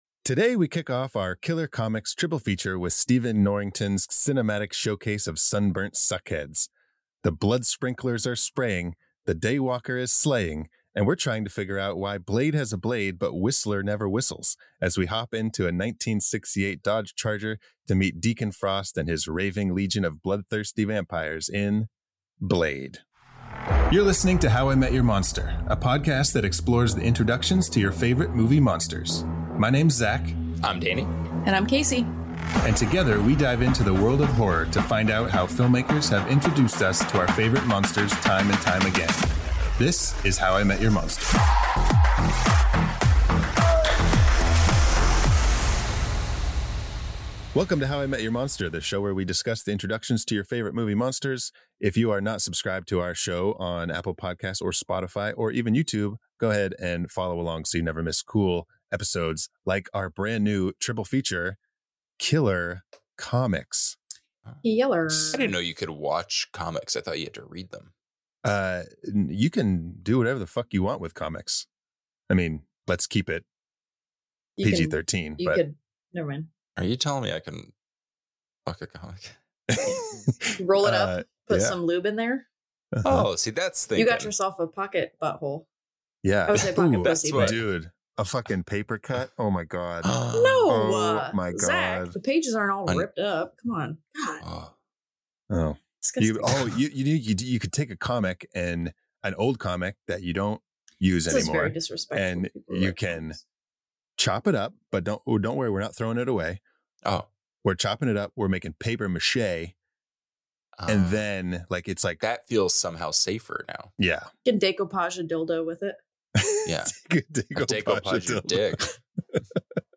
How I Met Your Monster is the horror-comedy podcast that explores the introductions of your favorite movie monsters!